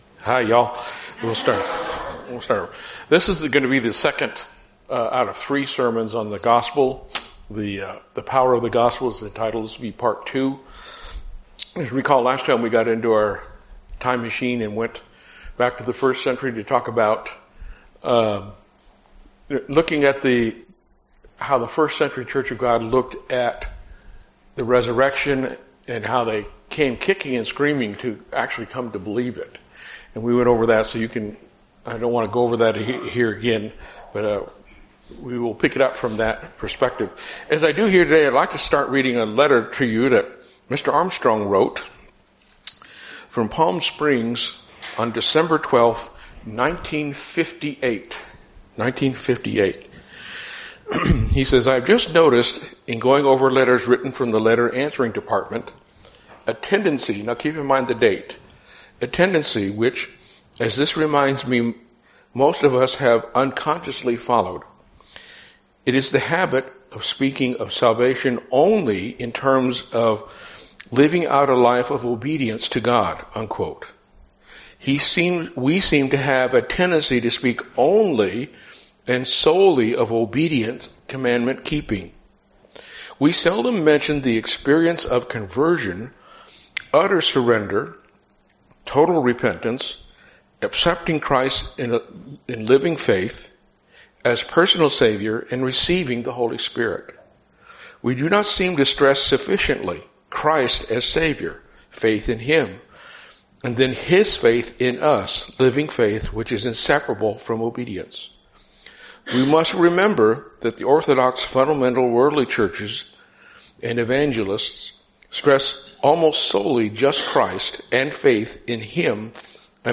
Learn more about the whole Gospel of Christ. As you do, the Power of the Gospel becomes clear. This message is Part 2 in a 3 part sermon series.